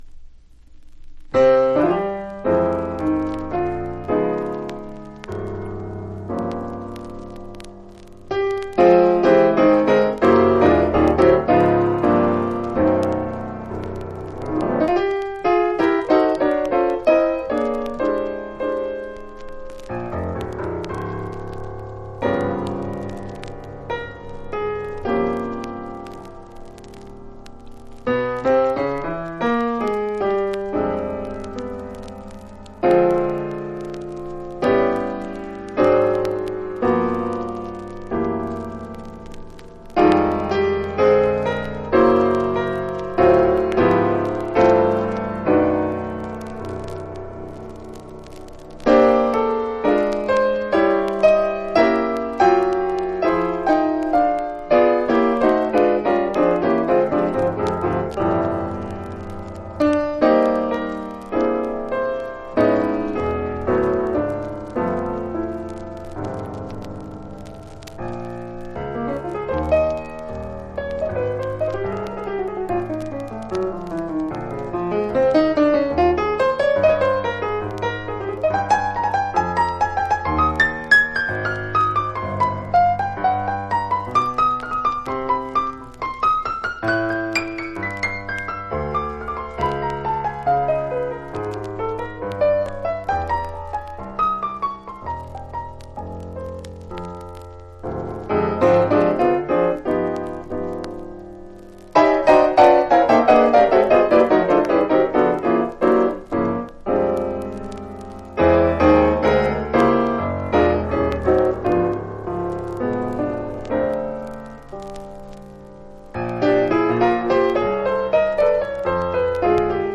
（MONO針で聴くとほとんどノイズでません）※曲…